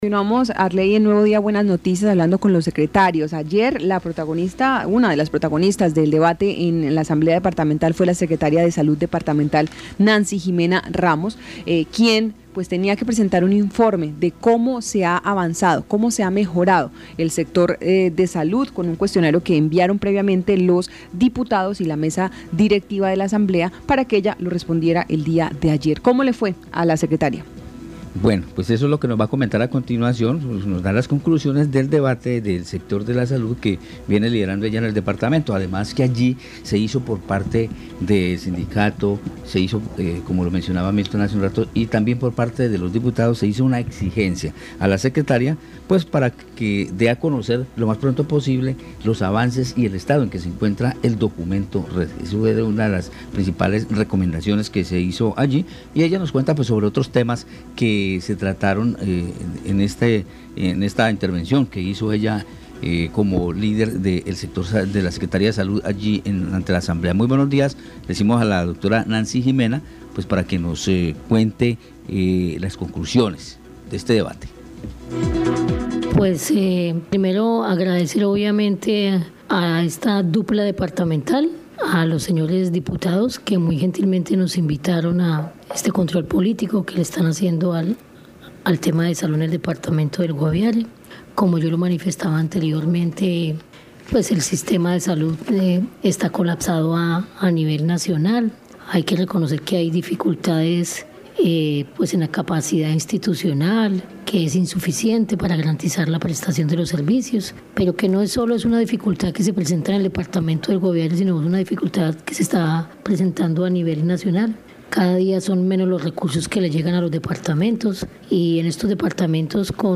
Debate control político a la salud